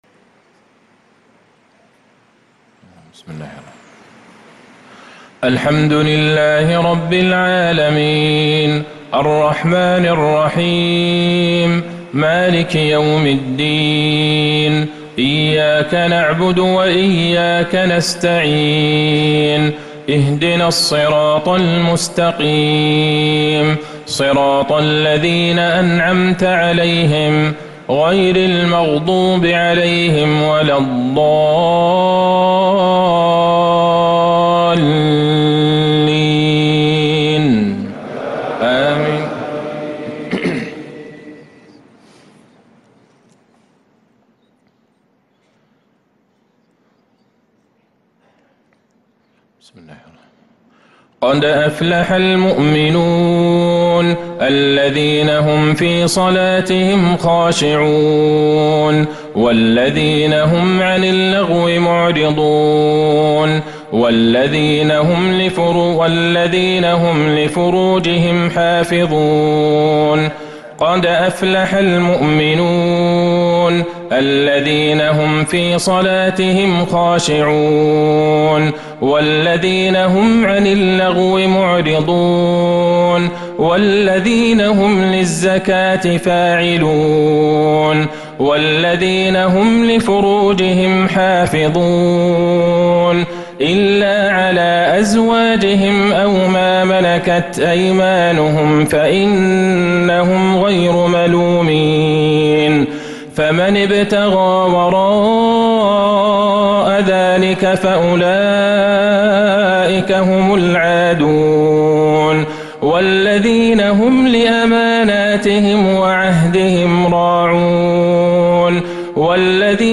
مغرب الجمعة ٧صفر١٤٤٧ من سورة المؤمنون ١-١٦ | magrib prayer from surah al-muminun 1-8-2025 > 1447 🕌 > الفروض - تلاوات الحرمين